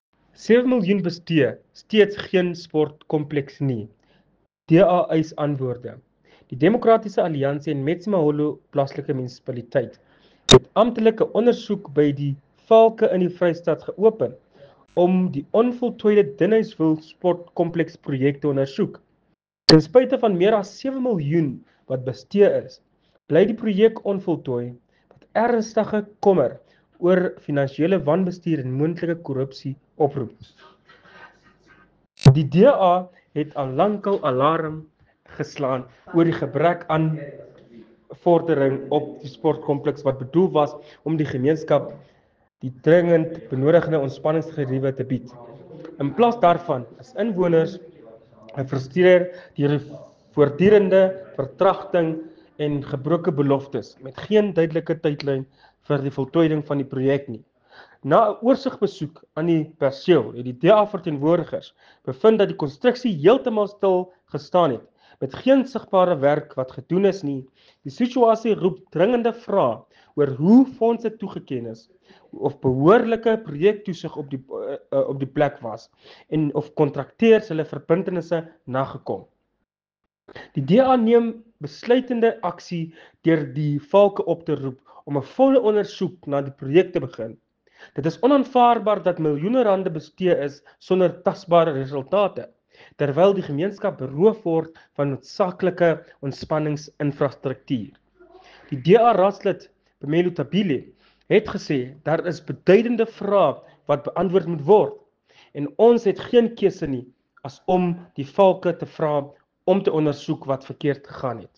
Afrikaans soundbites by Cllr Phemelo Tabile and